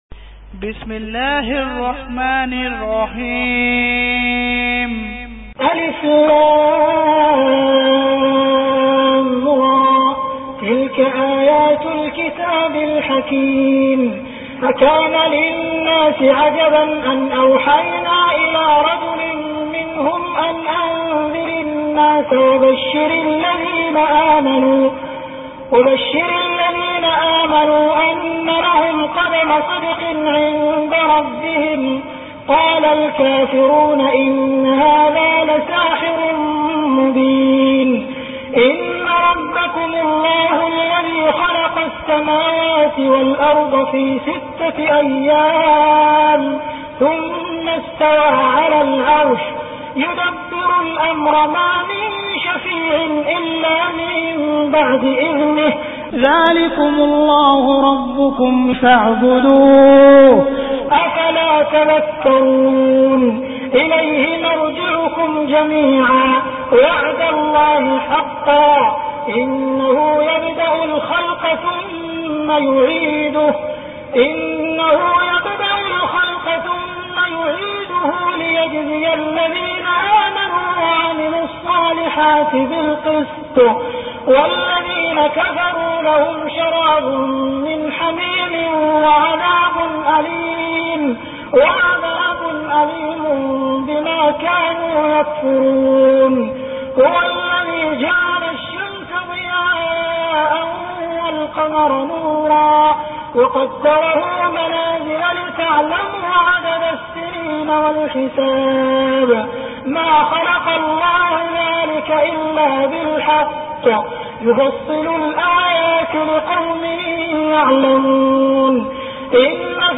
Surah Yunus Beautiful Recitation MP3 Download By Abdul Rahman Al Sudais in best audio quality.